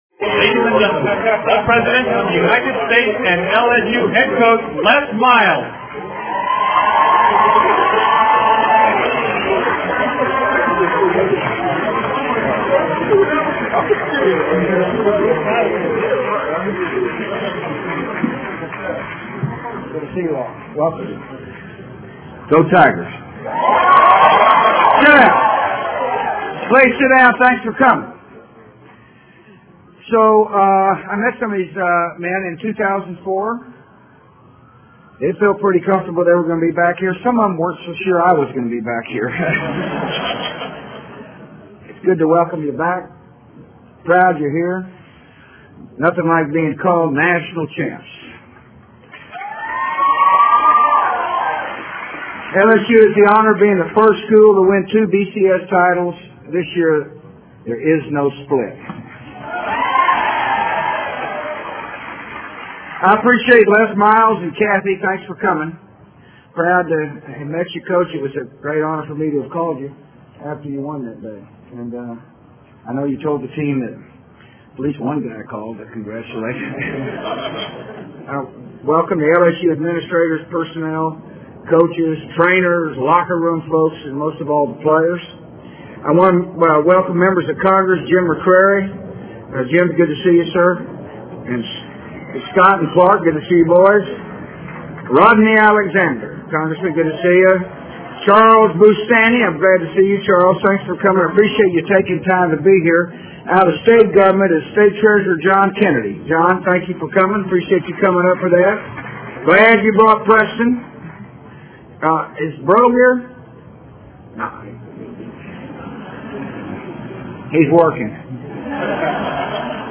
布什白宫迎接07年橄榄球冠军讲话(2008-04-07) 听力文件下载—在线英语听力室